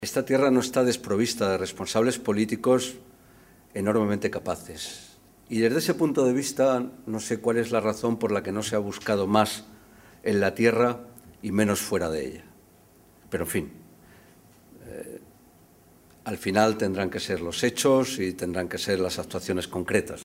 Fernando Moraleda, diputado nacional del PSOE
Cortes de audio de la rueda de prensa